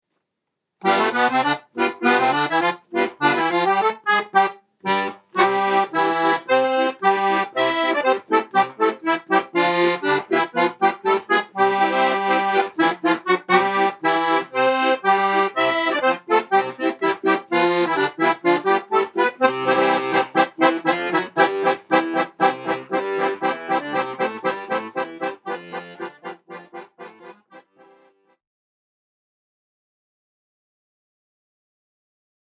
accordion solos